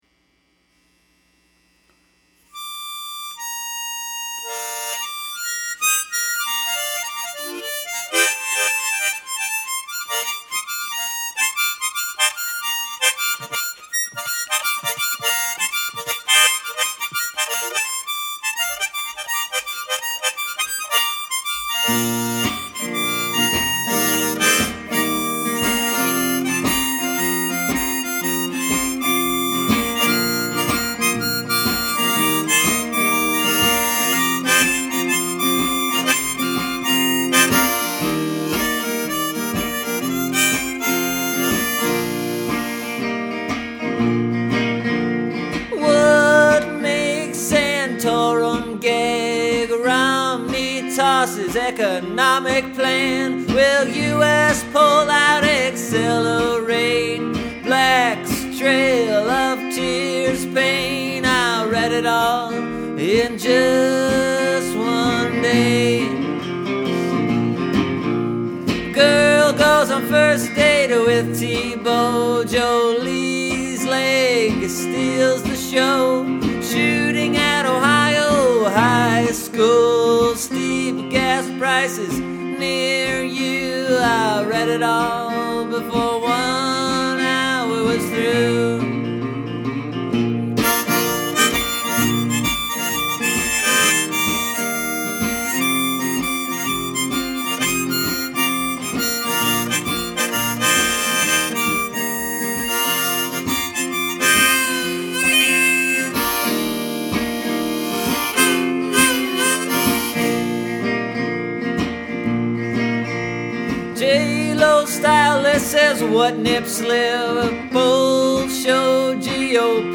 The headlines are all pretty hilarious the way they stacked up, plus I played electric guitar on this and made it all the way through. Can you hear me getting extra nervous when I play those fills in between the verses?